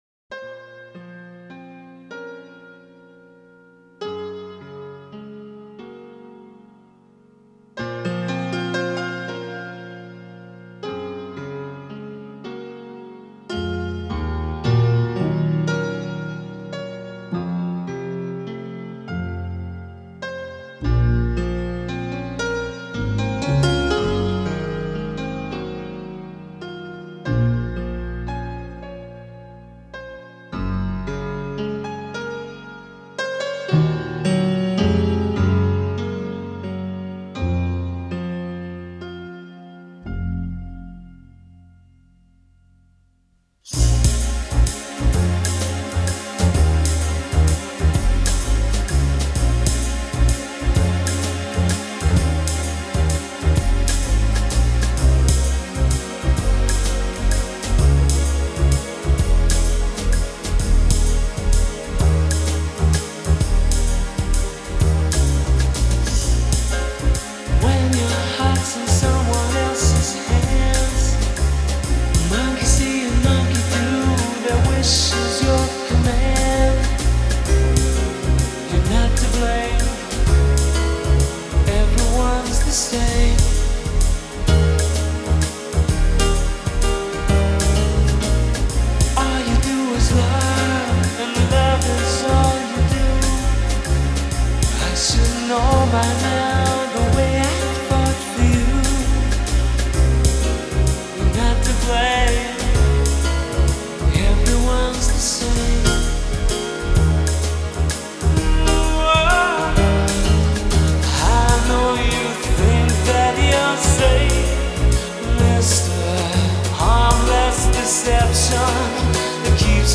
A masterclass in sophisticated arrangement
sophisto jazz waltz
It swings, man.